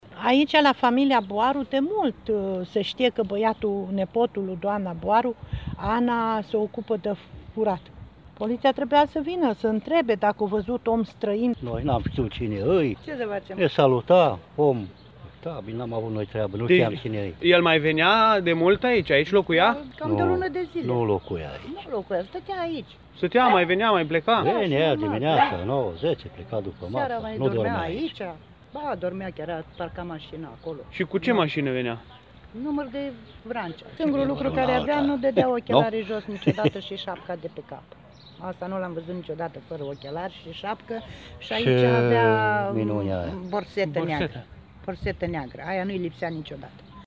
Mărturiile oamenilor din Remetea Mare, unde a fost capturat ucigașul polițistului din Timiș
voxuri-vecini-Remetea.mp3